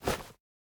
Minecraft Version Minecraft Version snapshot Latest Release | Latest Snapshot snapshot / assets / minecraft / sounds / item / bundle / remove_one1.ogg Compare With Compare With Latest Release | Latest Snapshot